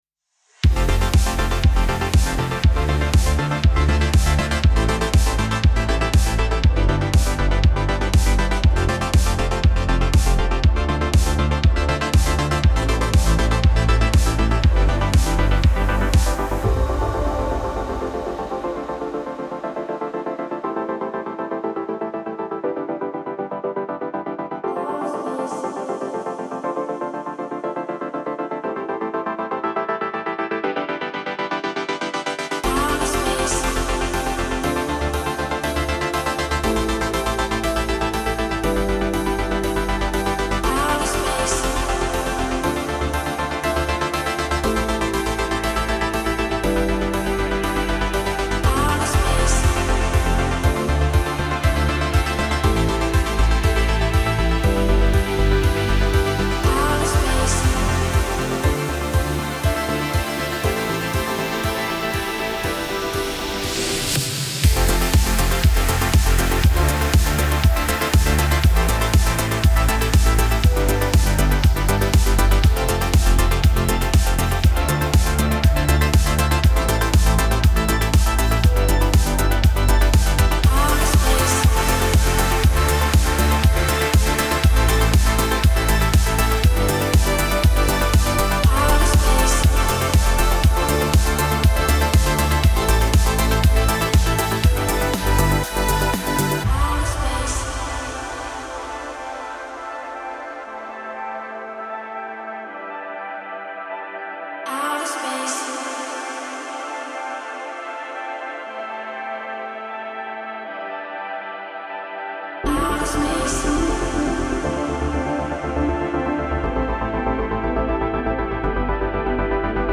الکترونیک , امید‌بخش , پر‌انرژی , رقص , موسیقی بی کلام
موسیقی بی کلام دنس